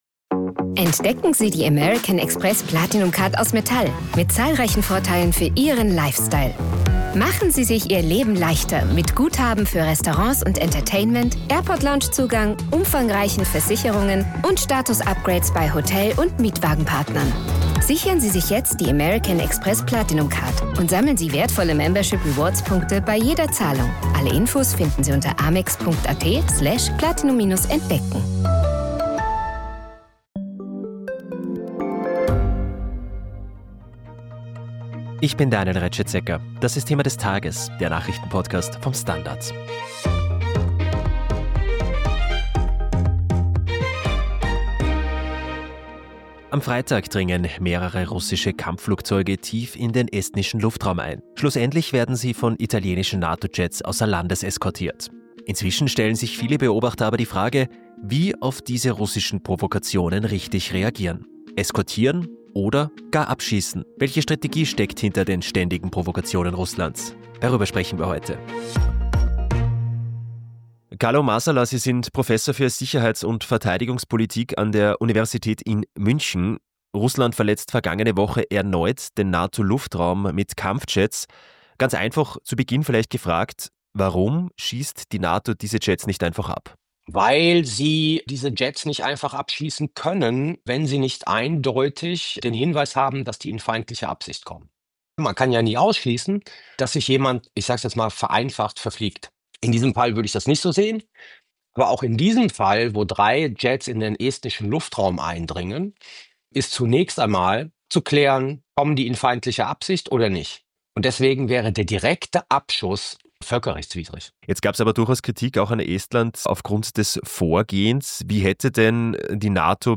Sicherheitsexperte Carlo Masala von der Universität in München erklärt das Vorgehen der Nato und zeigt auf, welche Strategie hinter den russischen Provokationen steckt.